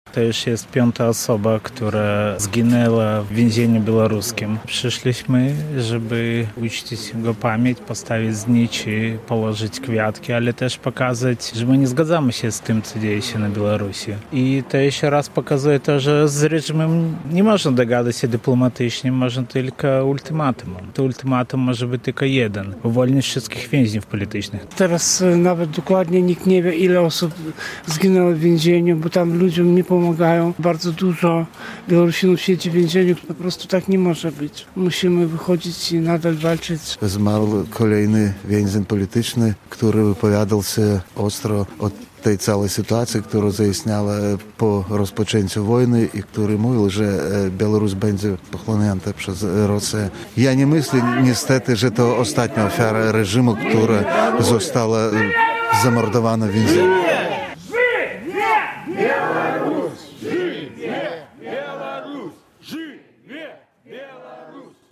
Wiadomości - Przed konsulatem uczczono pamięć tragicznie zmarłego białoruskiego opozycjonisty
W wtorek (20.02) przed konsulatem Republiki Białoruś w Białymstoku uczczono pamięć kolejnej ofiary reżimu.